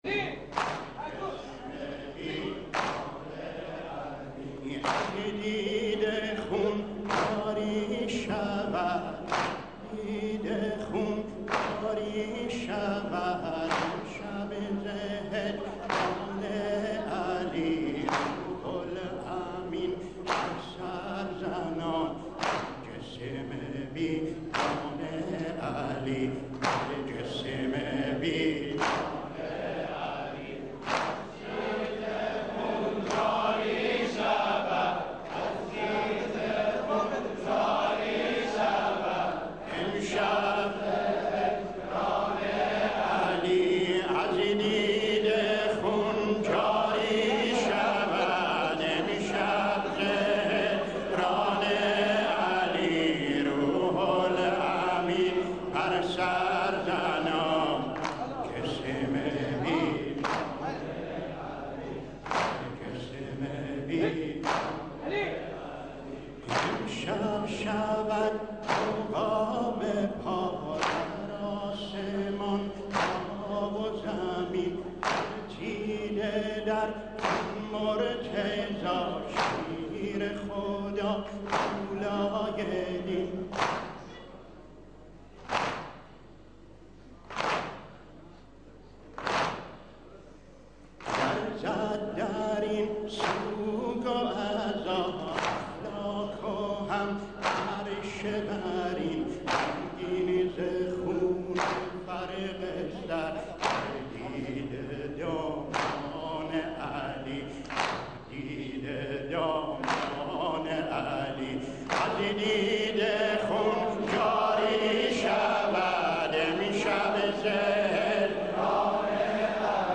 برچسب ها: نوحه بوشهری ، دانلود